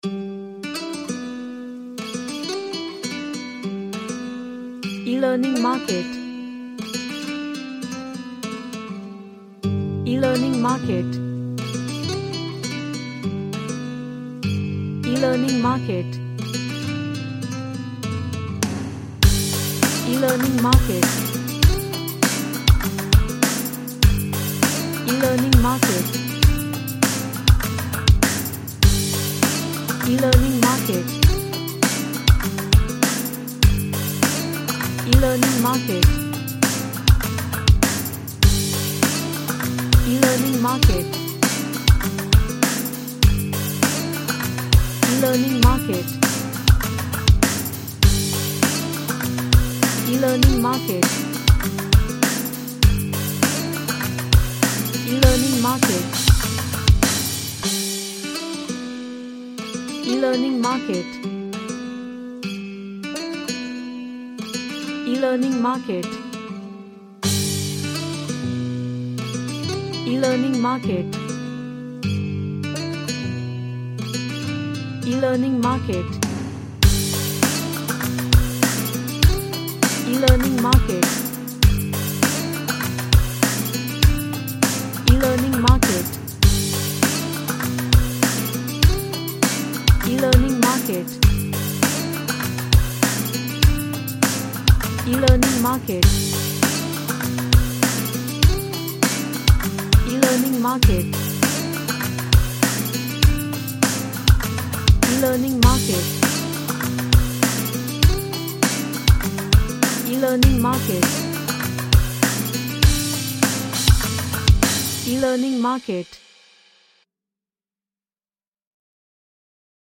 Homie vibe